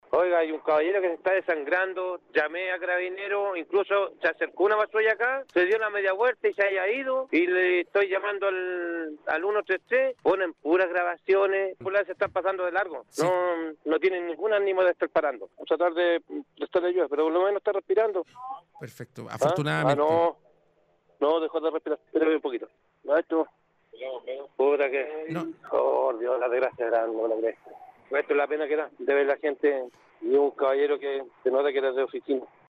y en medio de la transmisión de Radiograma Nocturno